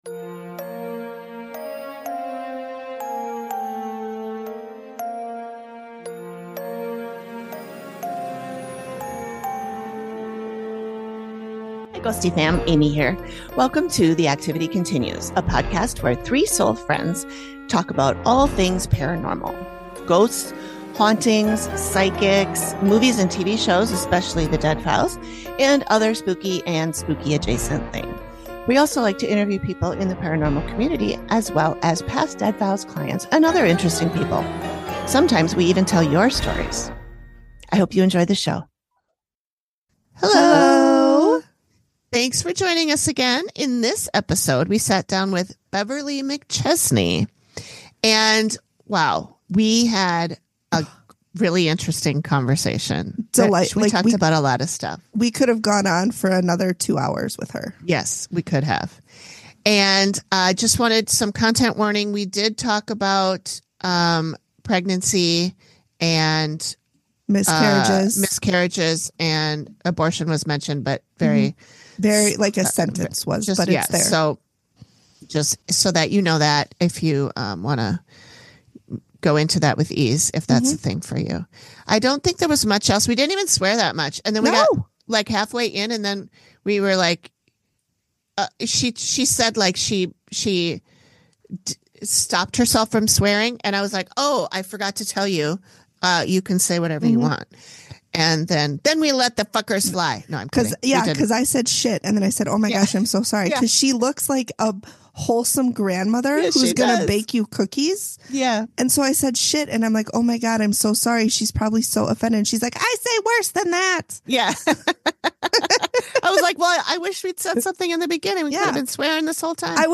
The Activity Continues is a podcast where three soul friends talk about all things paranormal. Ghosts, hauntings, psychics, movies and TV shows (especially The Dead Files) and other spooky and spooky-adjacent things as well.